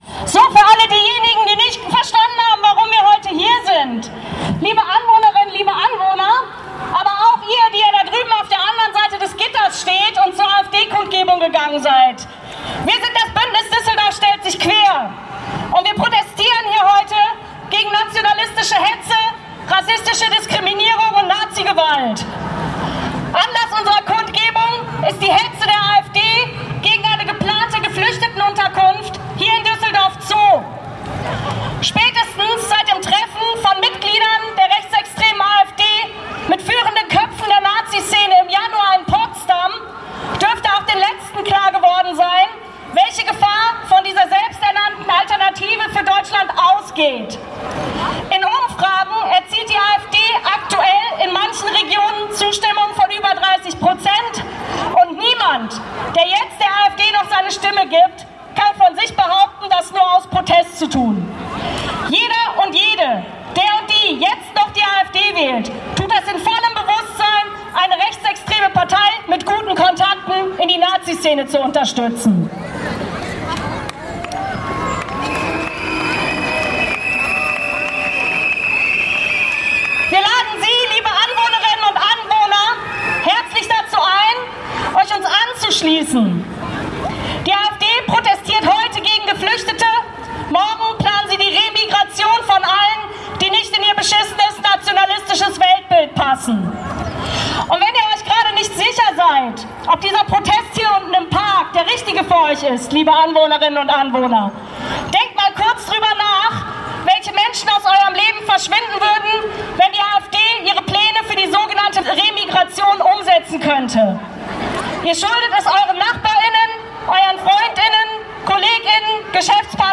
Kundgebung „Düsseldorf stellt sich quer gegen extreme Rechte und Rassismus!“ (Audio 4/7)